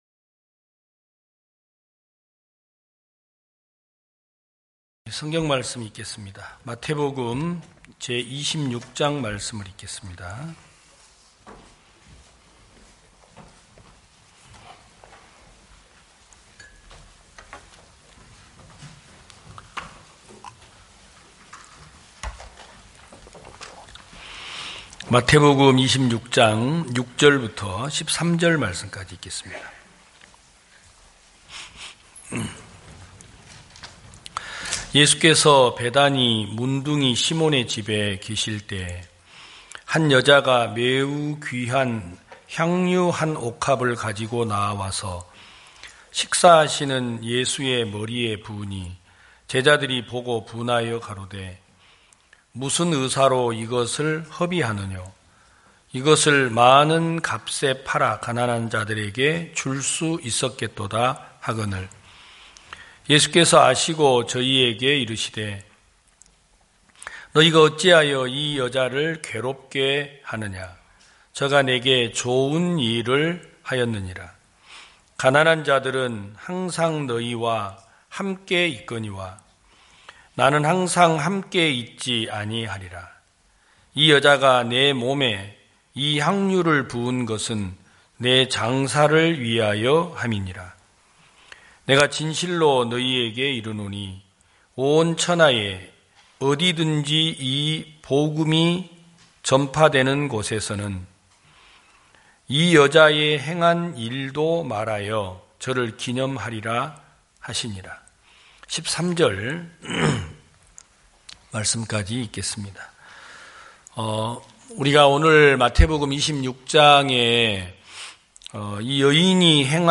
2021년 12월 19일 기쁜소식부산대연교회 주일오전예배
성도들이 모두 교회에 모여 말씀을 듣는 주일 예배의 설교는, 한 주간 우리 마음을 채웠던 생각을 내려두고 하나님의 말씀으로 가득 채우는 시간입니다.